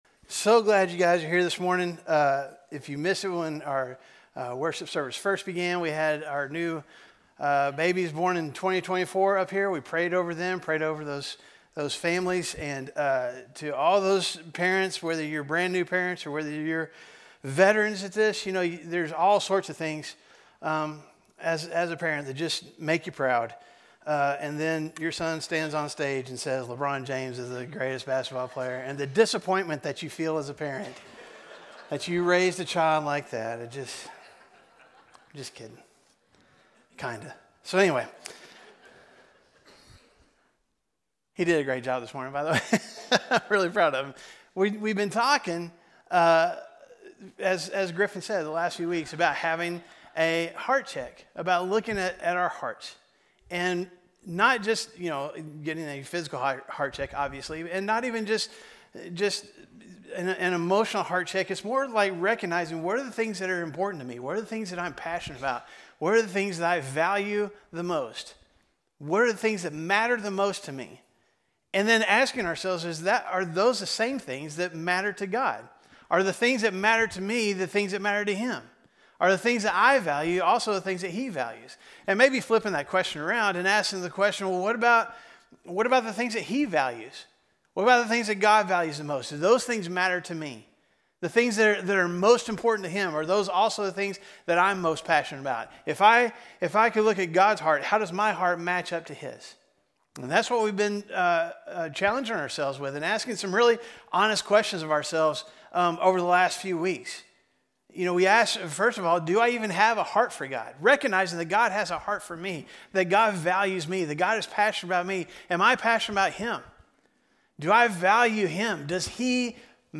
A message from the series "Heart Check."